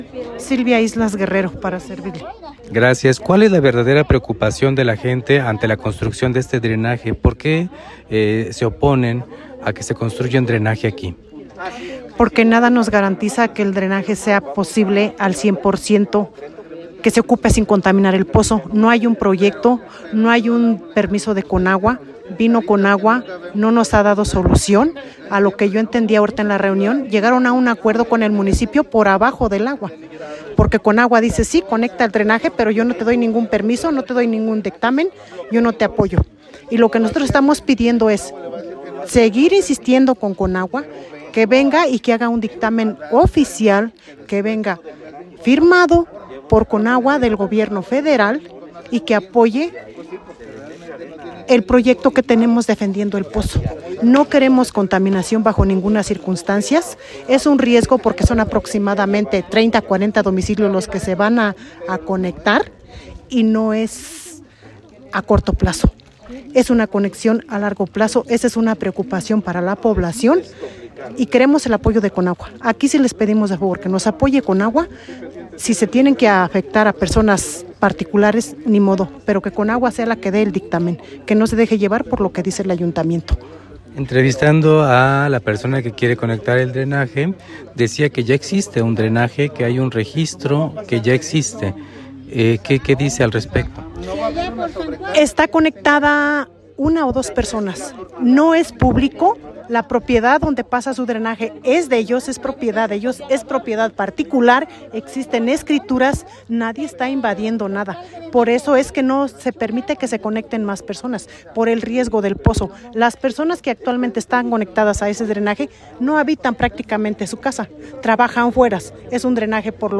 El pasado, viernes, vecinas y vecinos de la cabecera municipal de Huayacocotla se reunieron en el pozo Dexte convocados por la urgencia de la construcción de un drenaje.
La palabra es de del comité del pozo